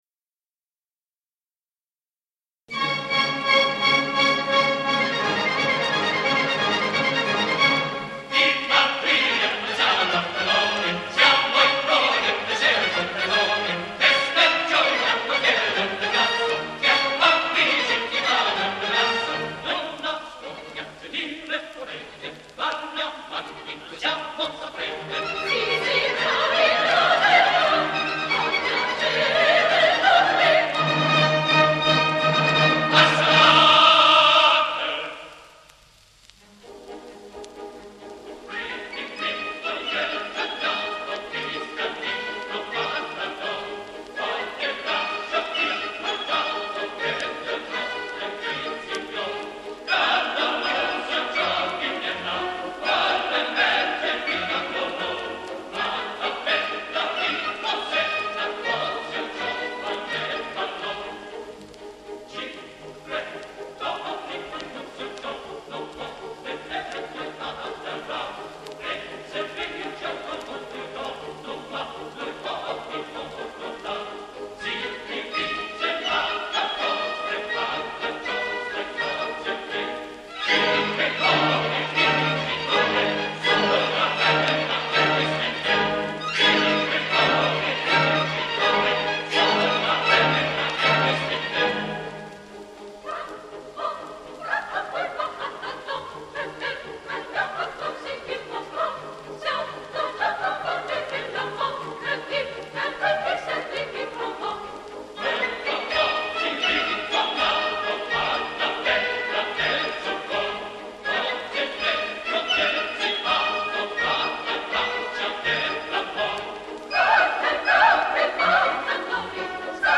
(coro di Mattadori)